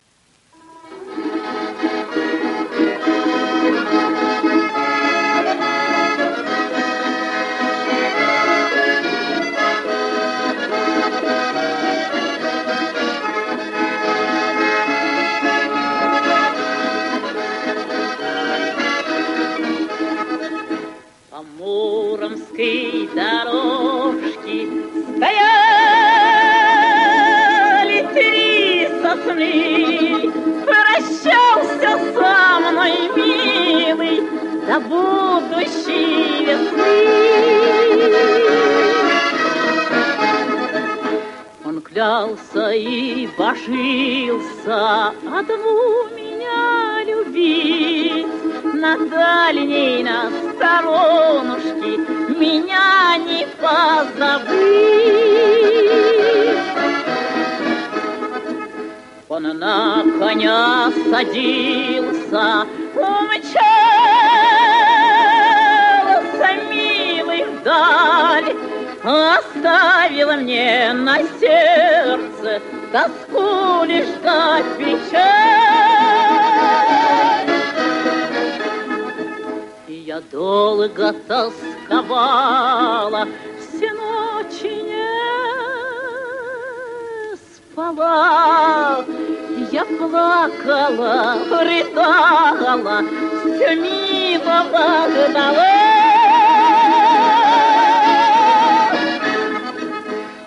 Вот фрагмент этой песни в классическом исполнении Лидии Андреевны Руслановой: